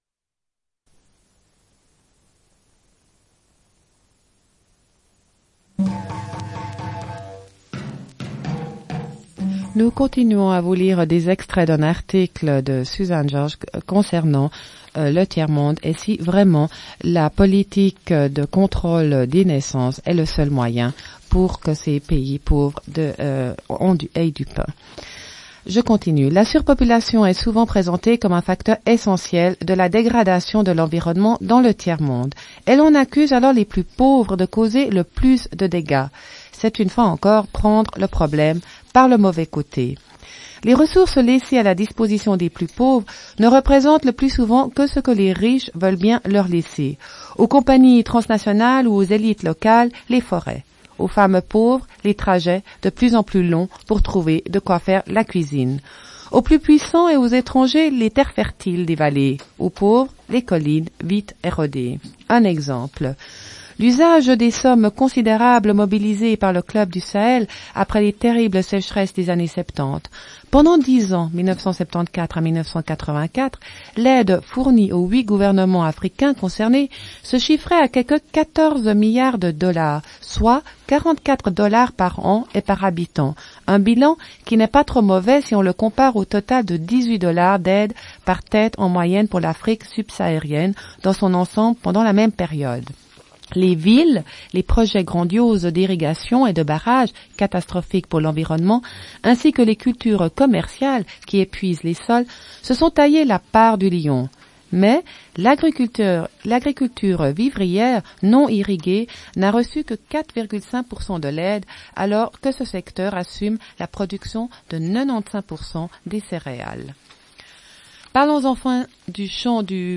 Une cassette audio, face A01:05:02
Bulletin d'information de Radio Pleine Lune.